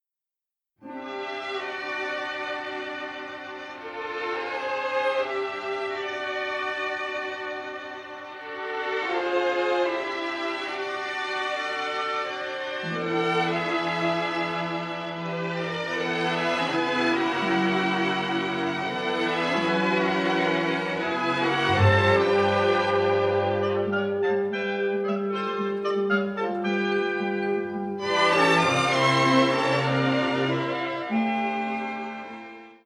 a lyrical main theme that bookends the film